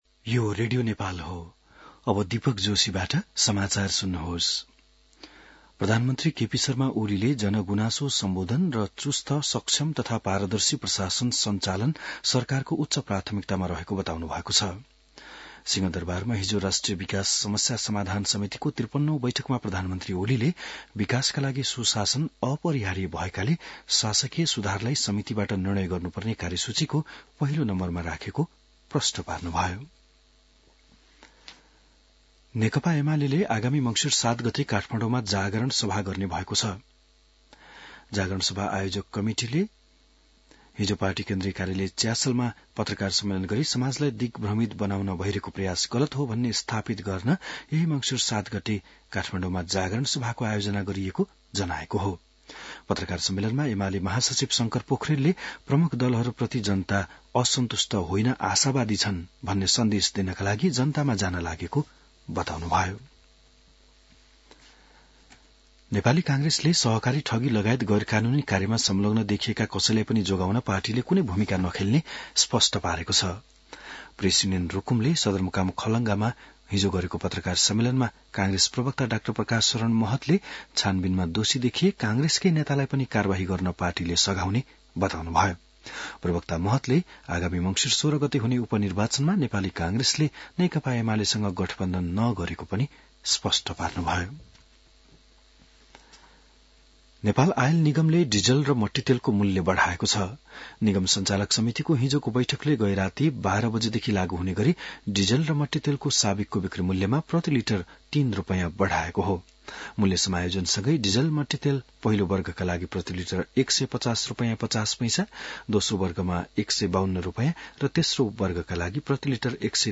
बिहान १० बजेको नेपाली समाचार : २ मंसिर , २०८१